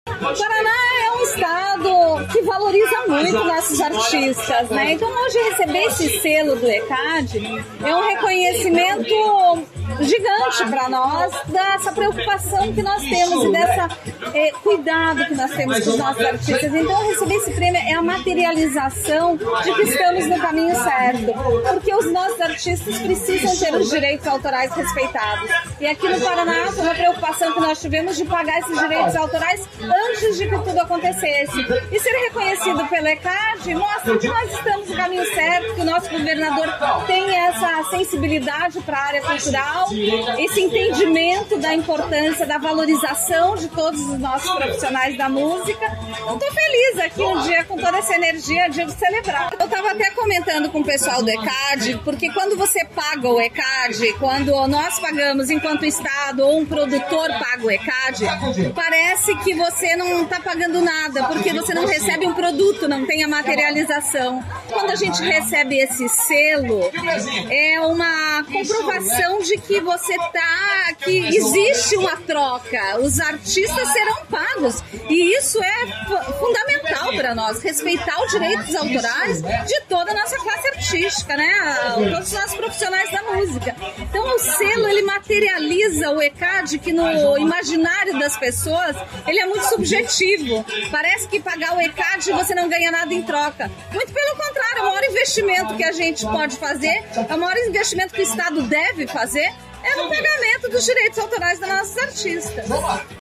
Sonora da secretária da Cultura, Luciana Casagrande Pereira, sobre os direitos autorais dos artistas paranaenses